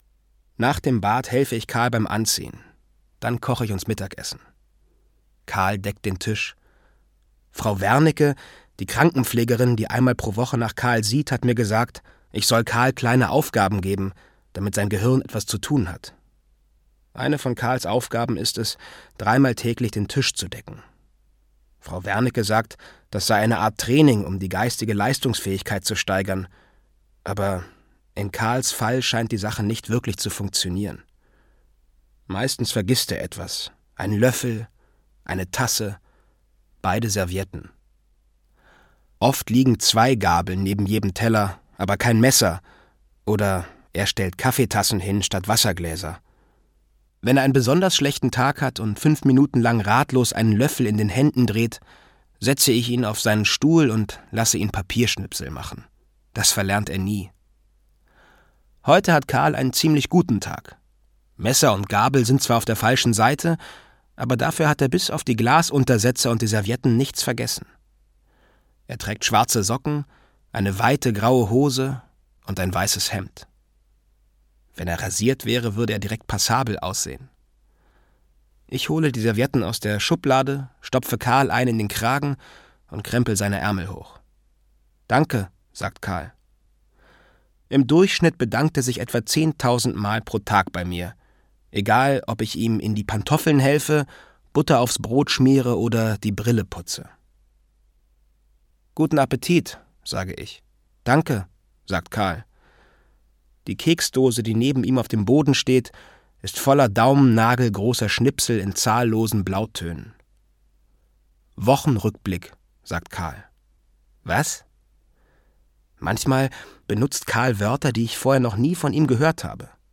Pampa Blues - Rolf Lappert - Hörbuch